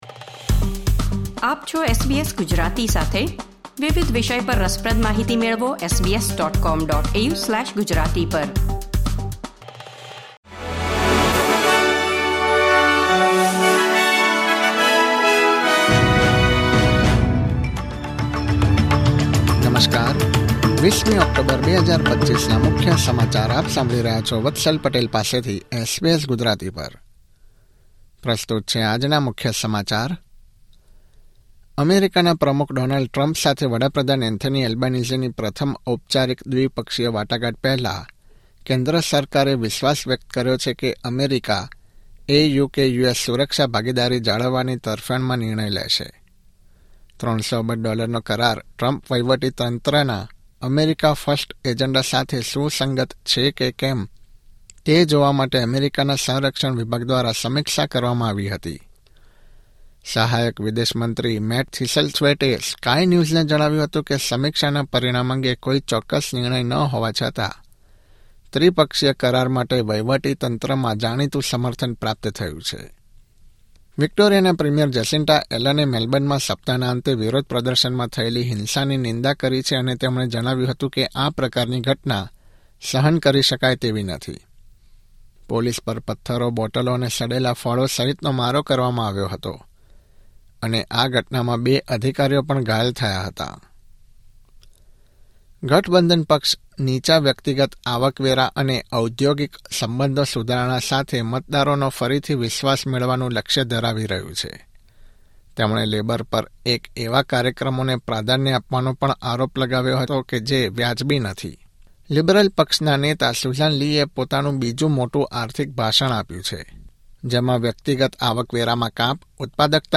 Listen to today's latest Australian news